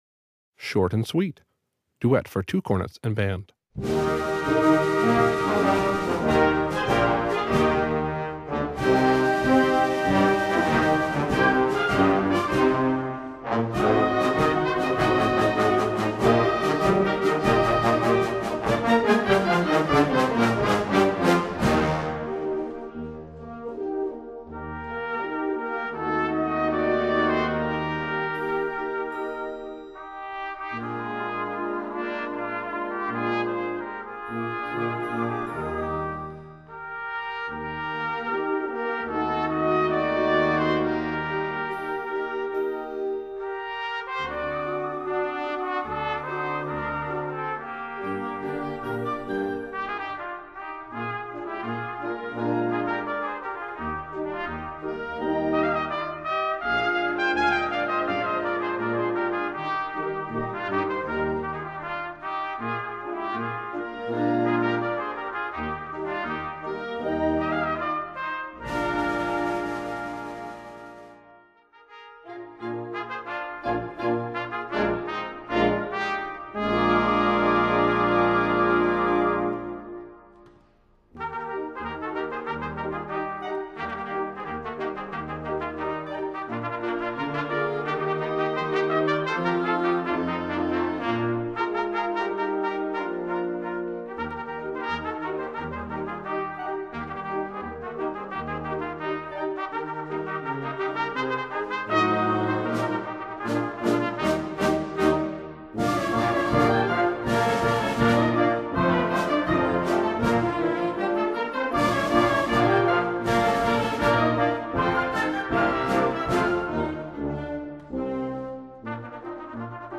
Gattung: Duo für Flügelhorn
Besetzung: Blasorchester